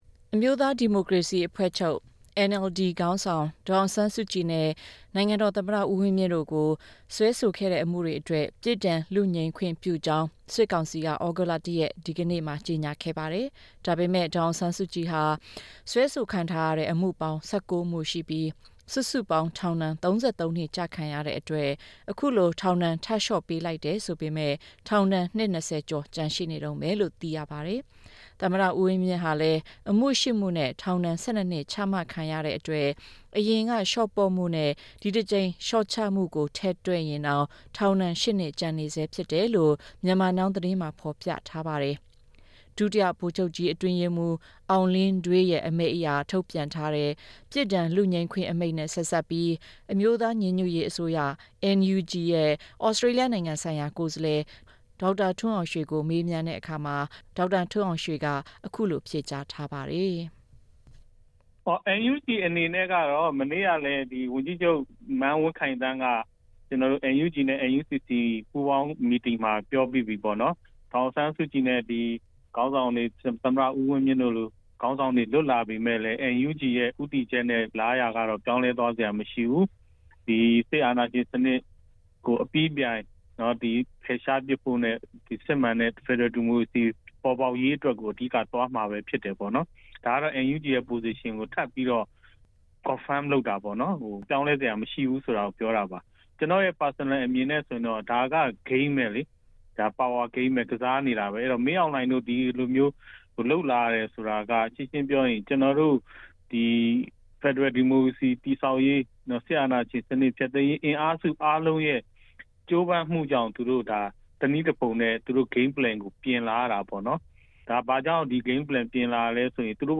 Dr. Tun Aung Shwe, NUG's Special Envoy to Australia Source: Supplied / Dr Tun Aung Shwe (Supplied)